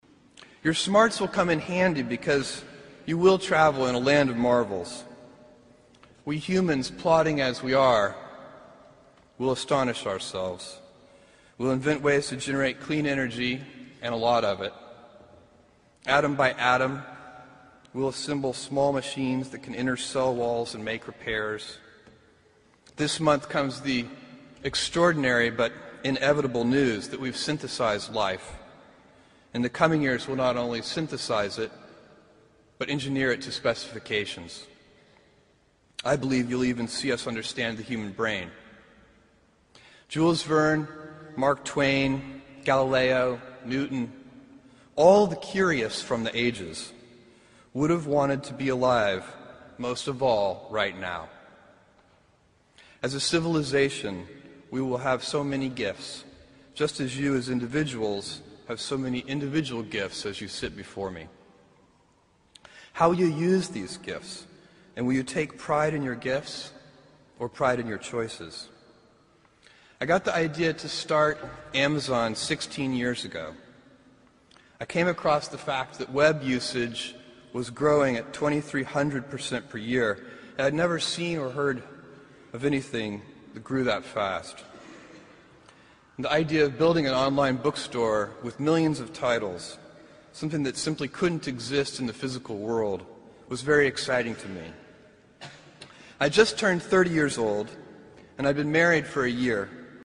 在线英语听力室名人励志英语演讲 第112期:选择塑造人生(4)的听力文件下载,《名人励志英语演讲》收录了19篇英语演讲，演讲者来自政治、经济、文化等各个领域，分别为国家领袖、政治人物、商界精英、作家记者和娱乐名人，内容附带音频和中英双语字幕。